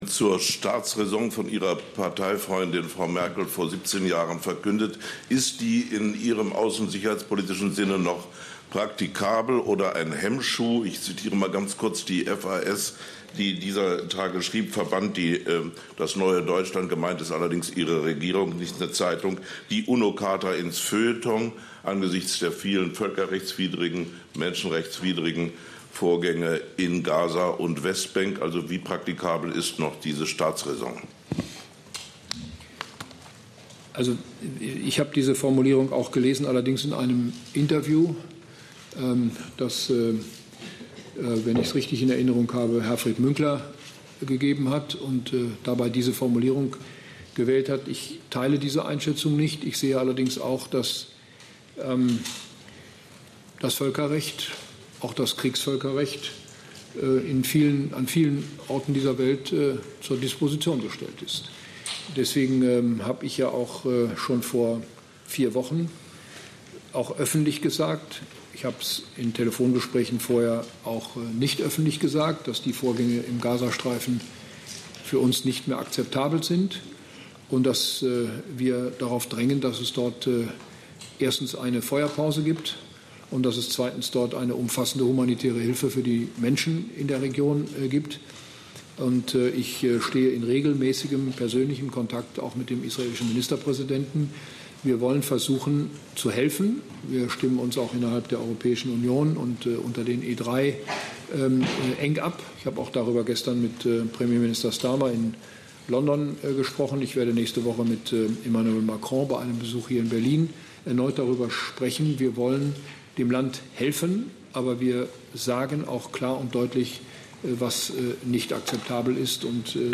Hören Sie den Ausschnitt mit Frage und Antwort hier.